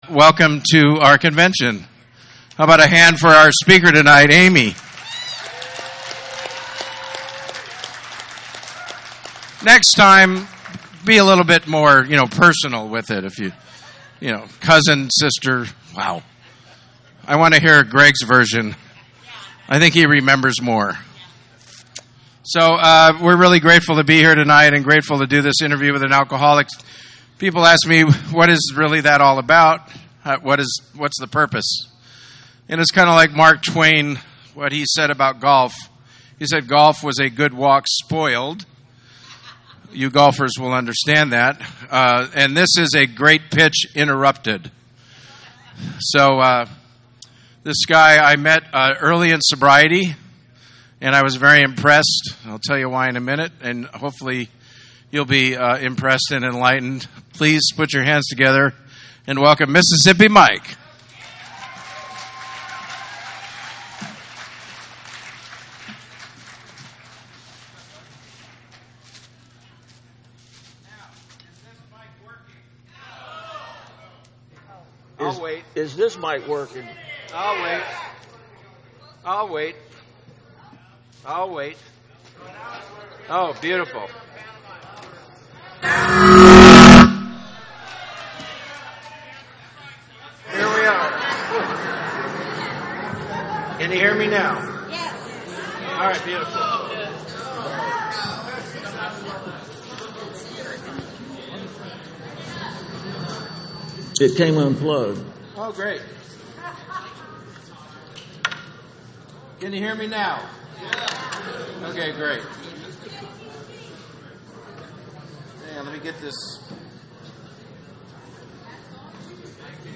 47th Annual San Fernando Valley AA Convention - Interview with and Alcoholic